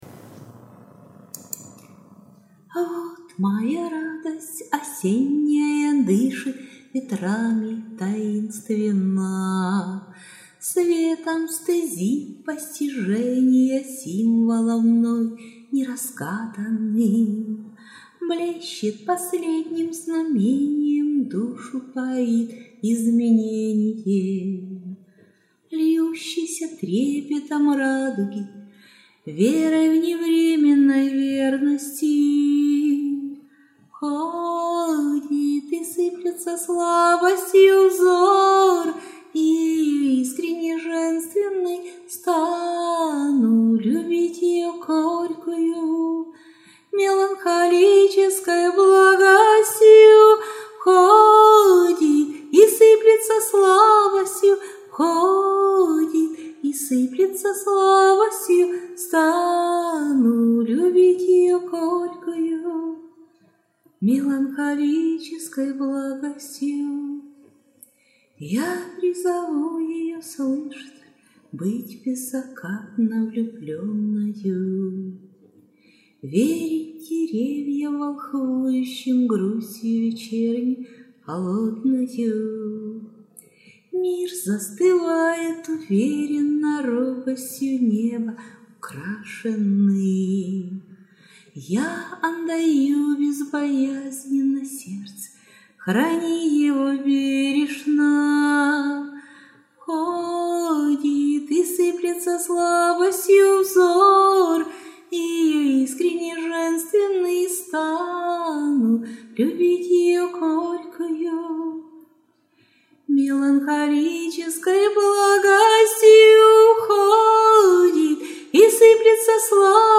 По-доброму заздрю ... такий проникливий і милий голос , а от я так не можу ...Душевна пісня 16 friends flowers
На моїй сторінці лише мелодія та акапелло,те,що МОЄ... biggrin biggrin biggrin